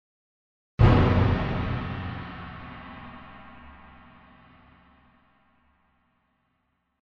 Je pensais que c'était un gong et un genre de gros taiko en même temps mais apparemment c'est un instrument réel...